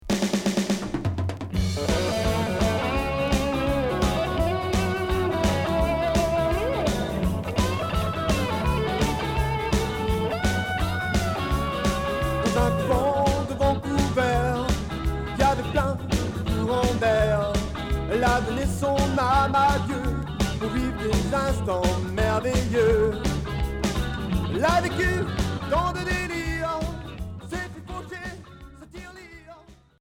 Rock Deuxième 45t retour à l'accueil